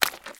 High Quality Footsteps
STEPS Swamp, Walk 04.wav